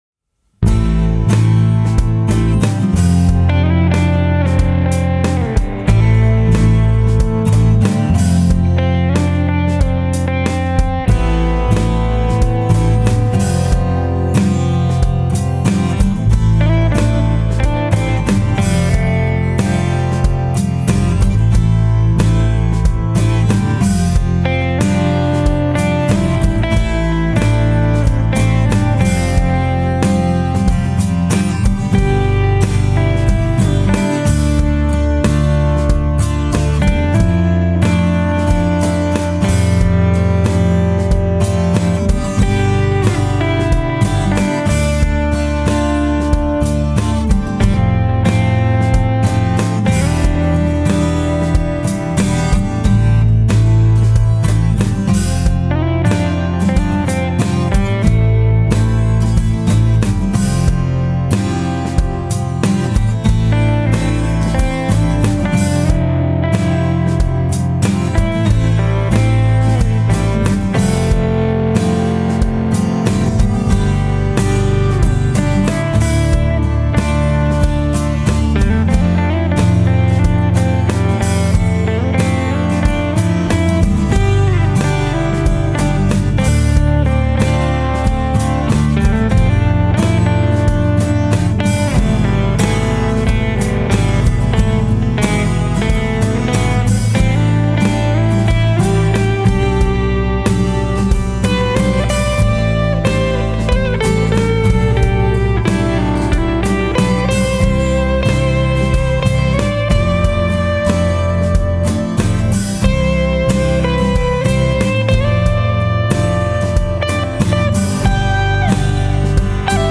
a quick mix/sketch from tonight’s studio time.